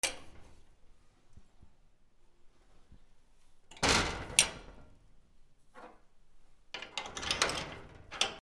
/ M｜他分類 / L05 ｜家具・収納・設備
『チンガ』